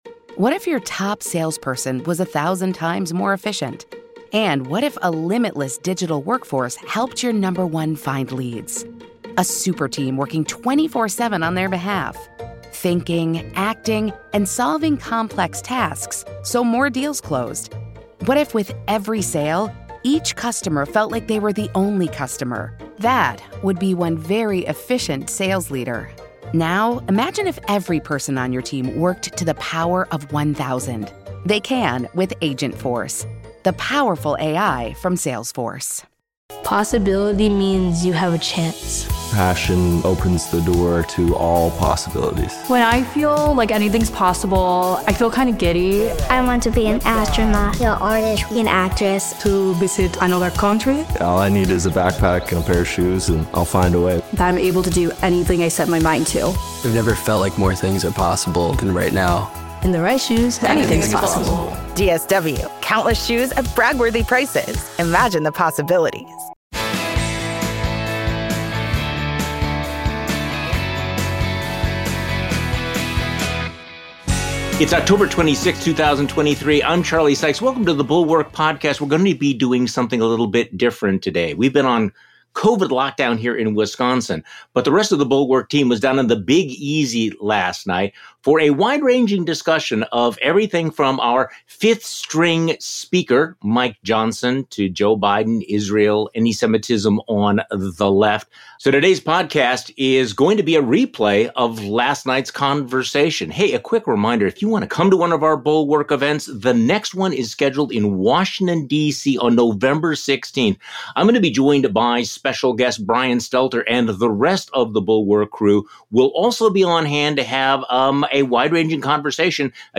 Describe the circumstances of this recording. The Bulwark: Live from New Orleans